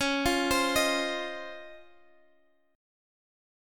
Listen to C#M9 strummed